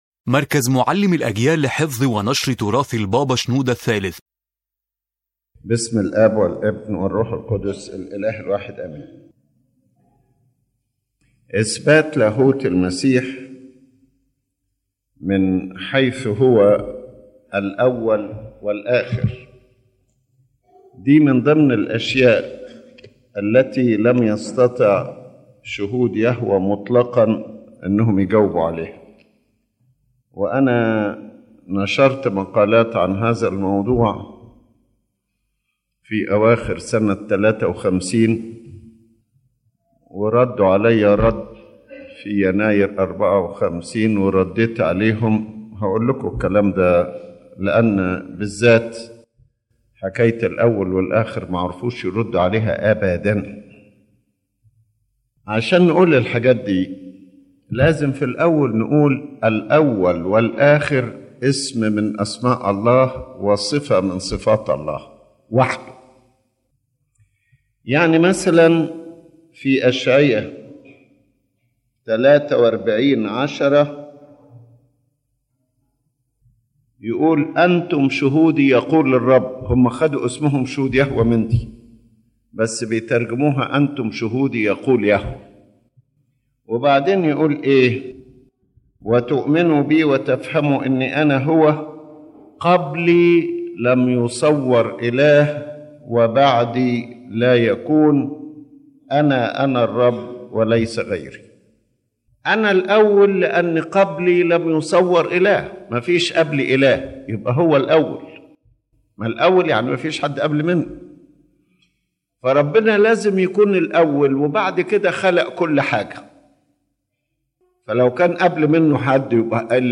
His Holiness Pope Shenouda III presents the proof of the divinity of Jesus Christ through the title “the First and the Last”, which belongs to God alone.
The lecture moves through Scriptural evidence, then through attributes such as the Holy One, the Good One, and Christ’s acceptance of worship. It also emphasizes the unique Sonship of Christ, distinct from all human sonship, and finally explains the title Son of Man from both its redemptive and divine aspects.